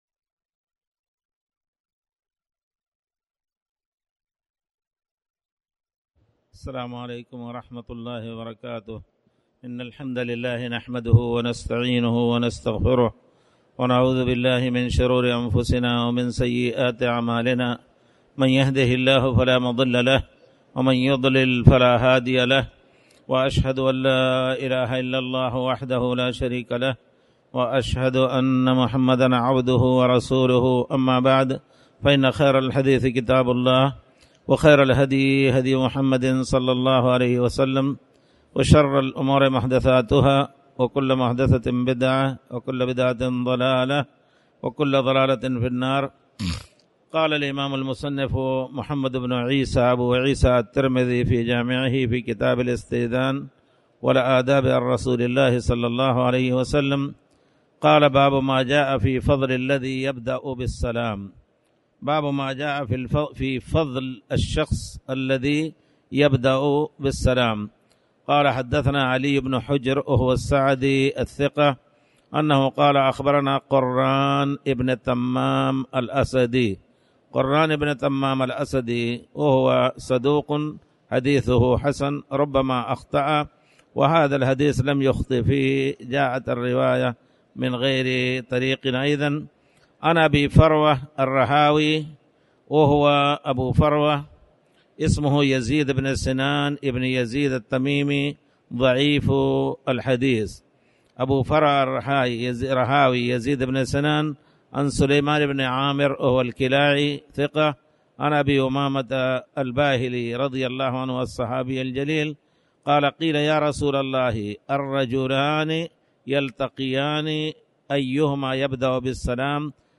تاريخ النشر ٥ شعبان ١٤٣٩ هـ المكان: المسجد الحرام الشيخ